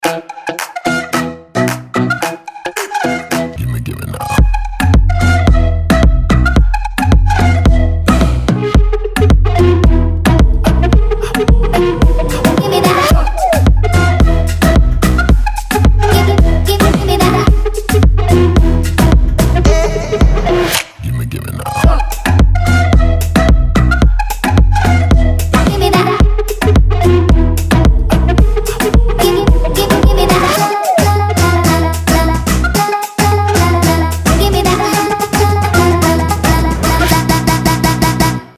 • Качество: 256, Stereo
поп
dance